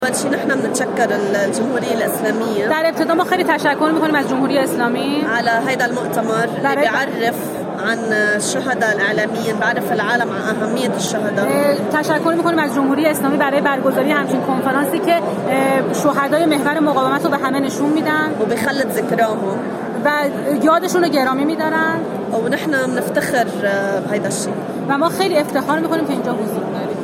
در گفت‌و‌گو با ایکنا